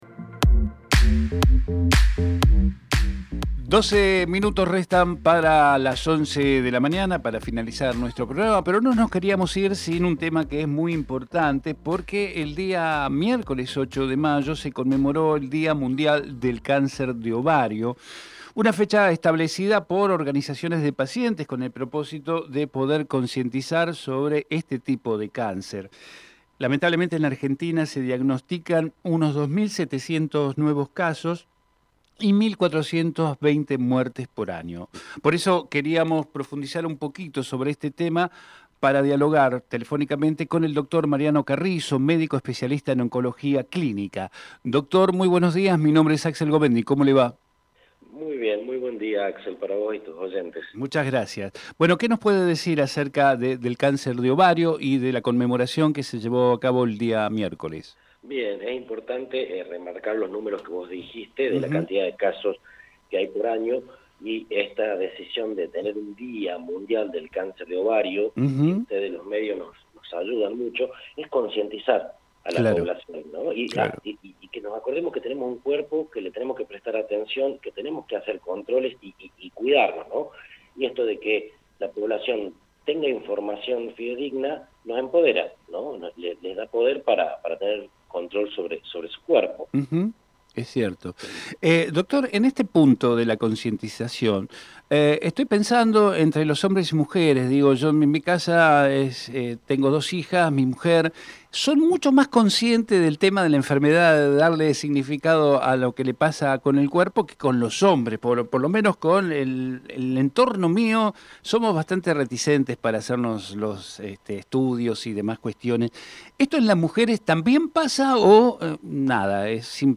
Compartimos entrevista